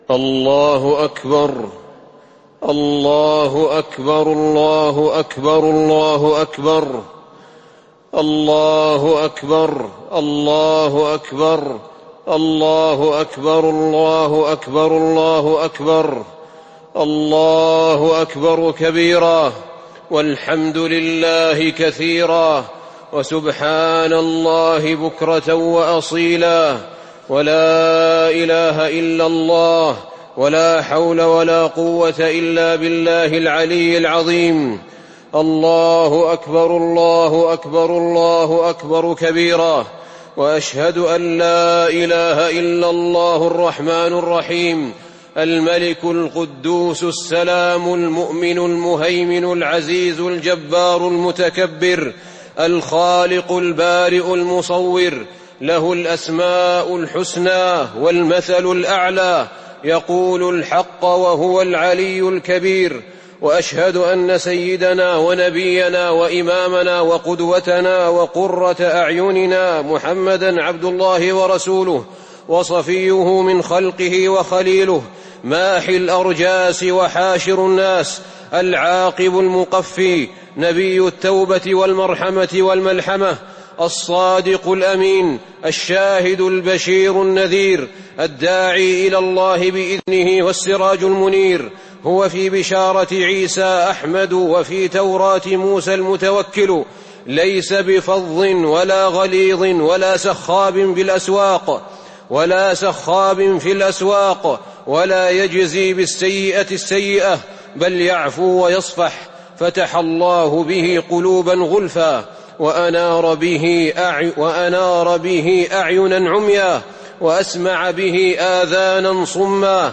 خطبة عيد الفطر- المدينة - الشيخ أحمد بن طالب
تاريخ النشر ١ شوال ١٤٤٢ هـ المكان: المسجد النبوي الشيخ: فضيلة الشيخ أحمد بن طالب بن حميد فضيلة الشيخ أحمد بن طالب بن حميد خطبة عيد الفطر- المدينة - الشيخ أحمد بن طالب